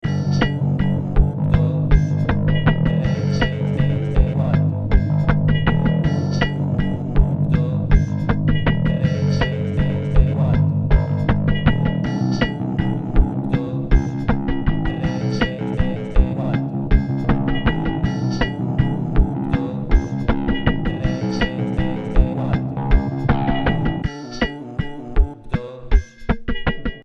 Bucle de Electro Punk
Música electrónica
punk
repetitivo
sintetizador